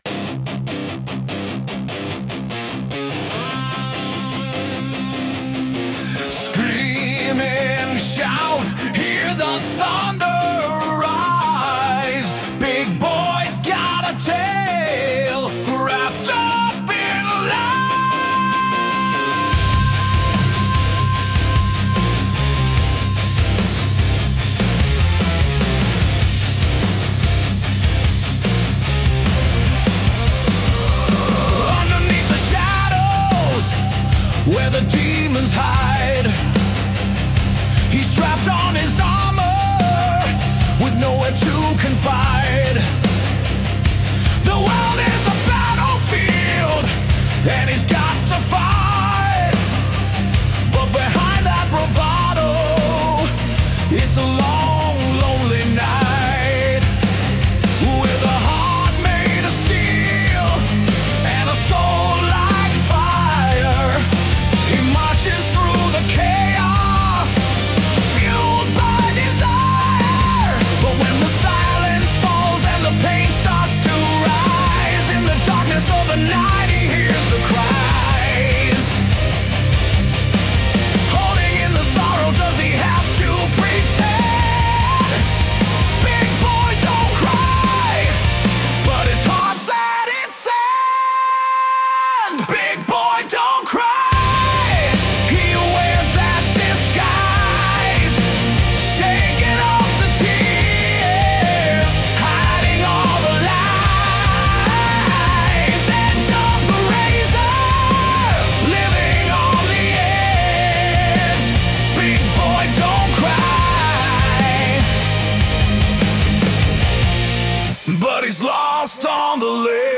New metal single